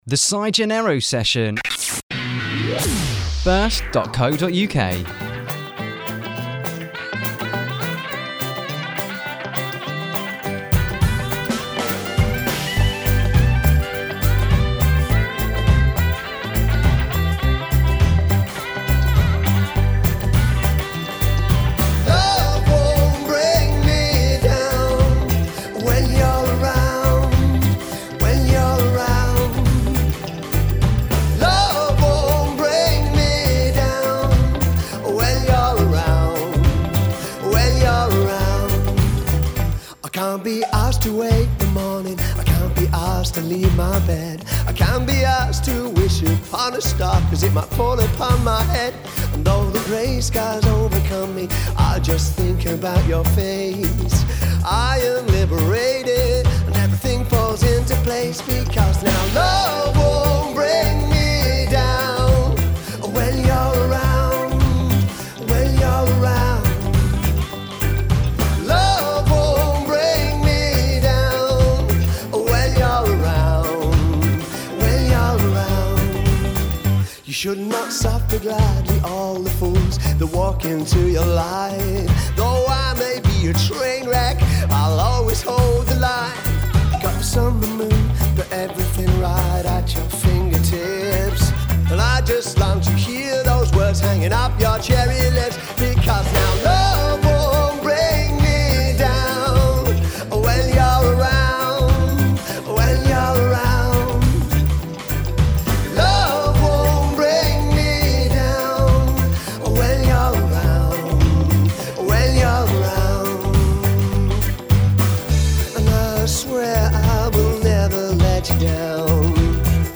in the BIRSt studio
performs in-studio renditions